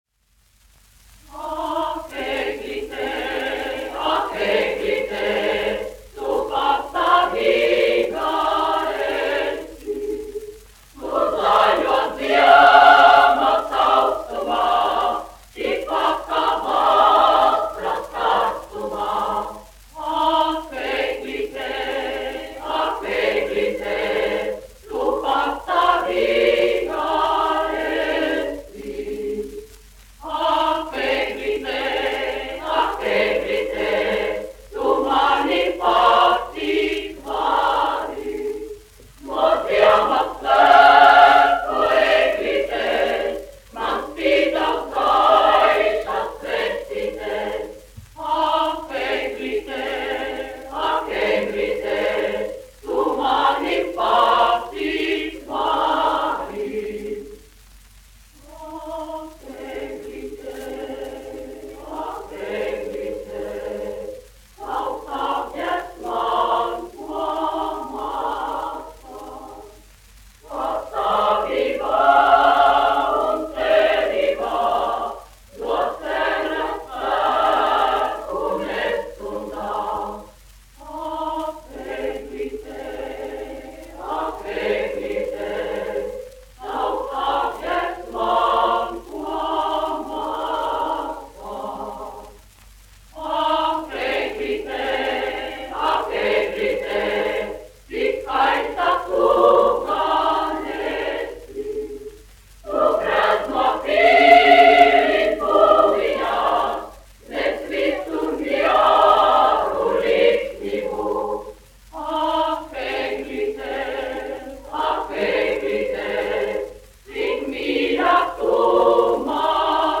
Reitera koris, izpildītājs
1 skpl. : analogs, 78 apgr/min, mono ; 25 cm
Ziemassvētku mūzika
Latvijas vēsturiskie šellaka skaņuplašu ieraksti (Kolekcija)